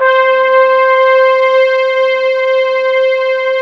ORCHEST.C5-R.wav